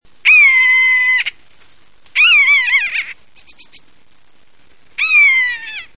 Myszołów - Buteo buteo
głosy